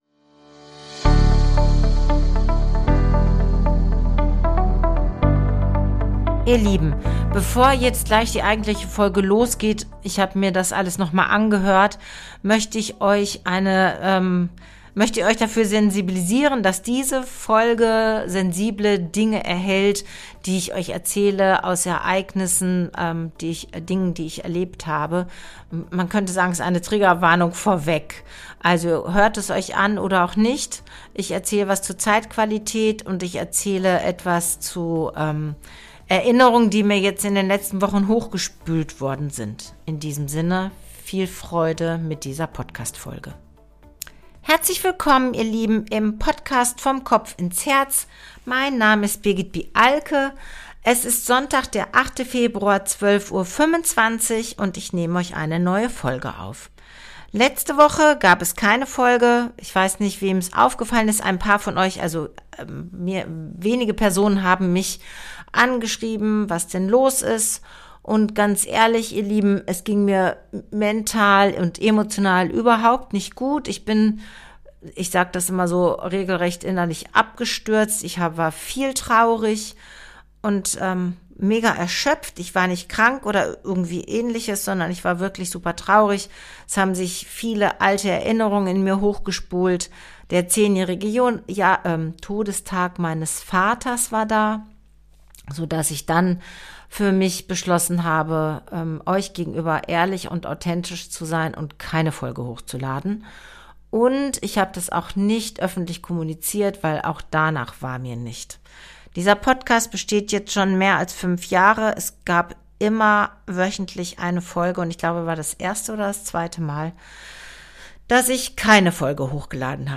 Am Ende der Folge hörst du einen Song, der aus genau dieser Stimmung heraus entstanden ist.